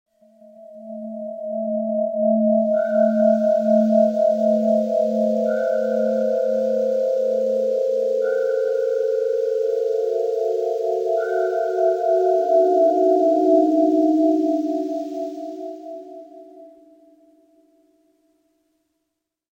SFX惊悚诡异的恐怖声音音效下载
SFX音效